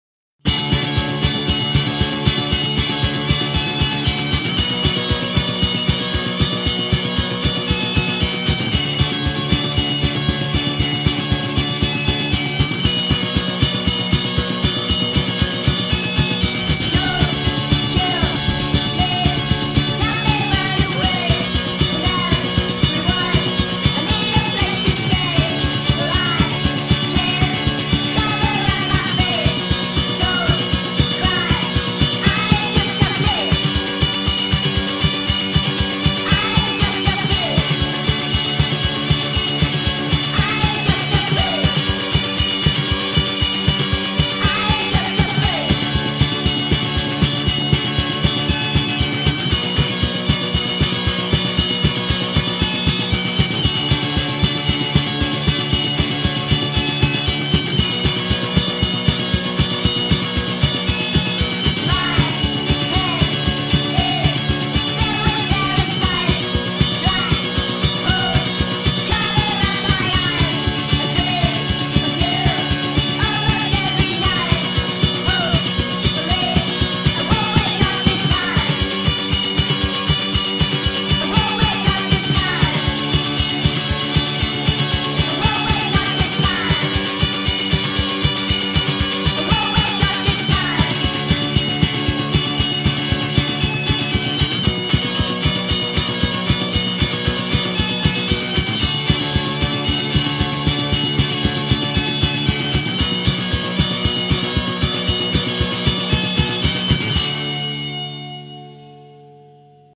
غمگین
غمگین خارجی